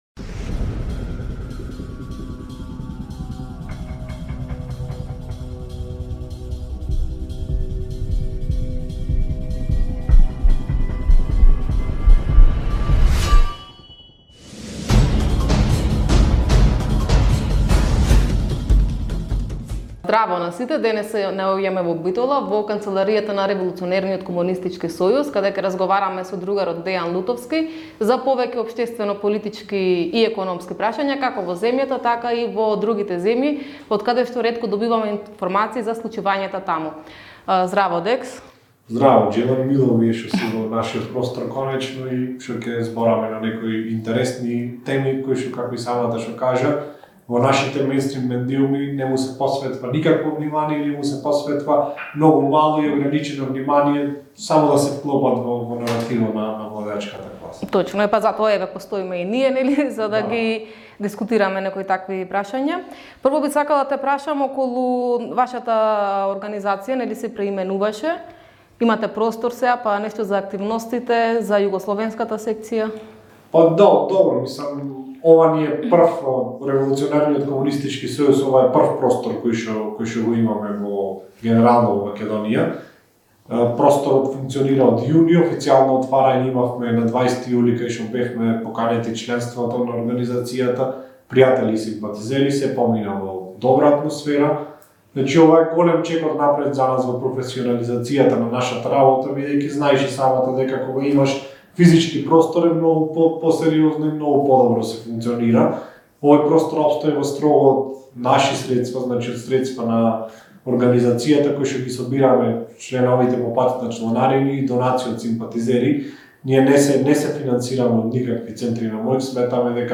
ИНТЕРВЈУ: Нѐ очекуваат ли револуционерни промени? / INTERVISTË: A na presin ndryshime revolucionare?